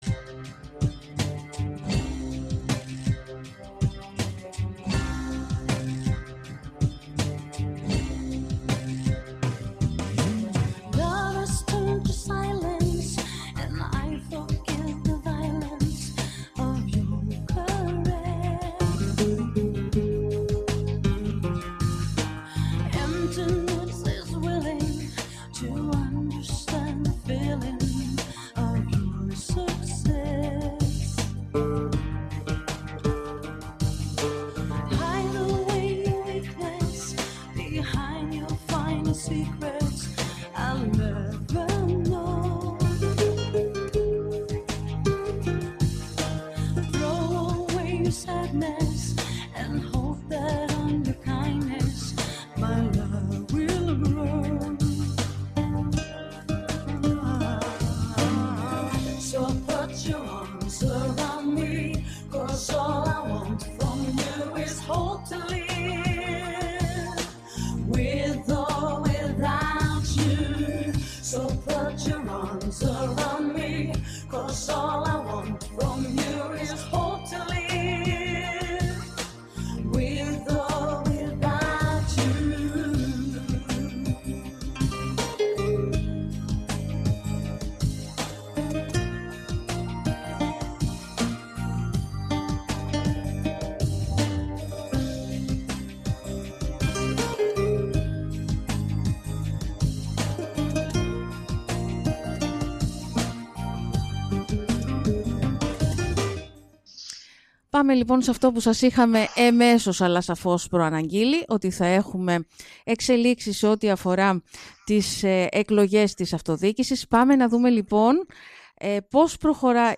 Τους λόγους που τον οδήγησαν να συγκροτήσει παράταξη για να διεκδικήσει το Δήμο Ρόδου στις προσεχείς αυτοδιοικητικές εκλογές εξήγησε μιλώντας στον Sky